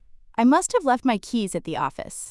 「マスタブ」と聞こえましたよね。
「must have」の「h」は聞こえず「mustave」「マスタブ」